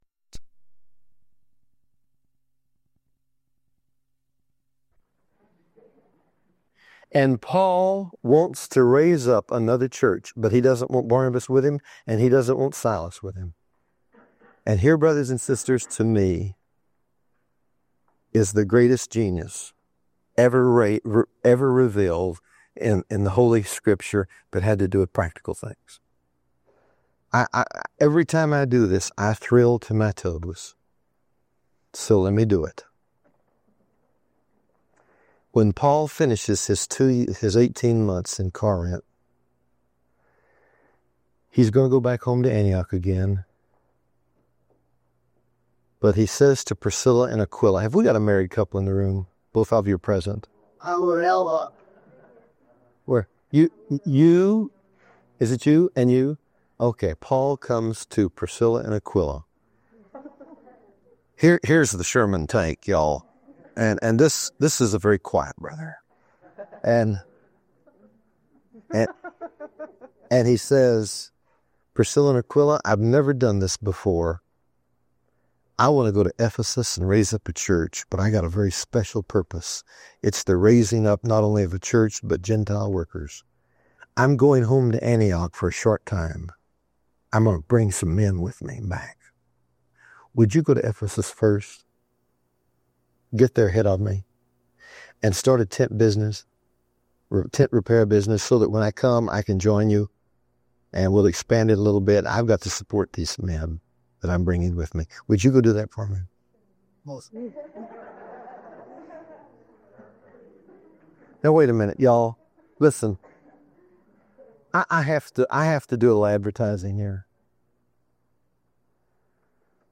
Discover how Paul strategically raised up the church in Rome and trained Gentile workers in this powerful background teaching on Romans.